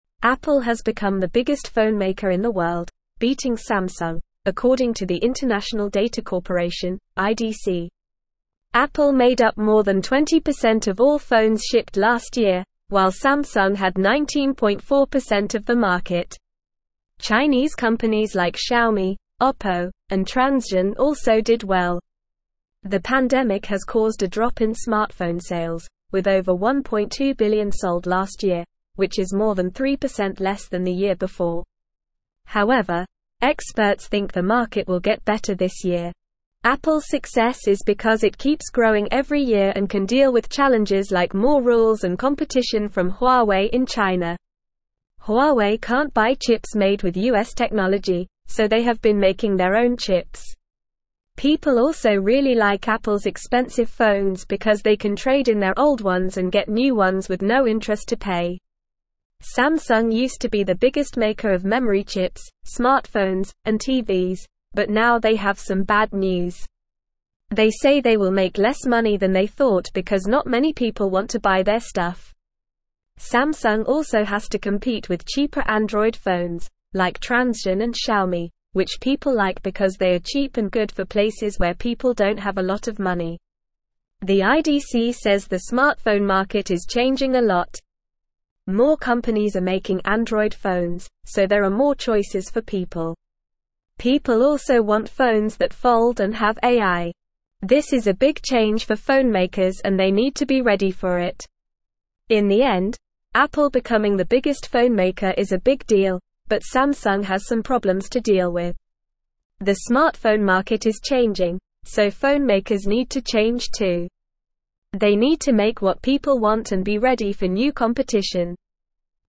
Normal
English-Newsroom-Upper-Intermediate-NORMAL-Reading-Apple-Surpasses-Samsung-as-Worlds-Largest-Phonemaker.mp3